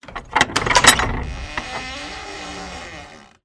DoorOpen1.wav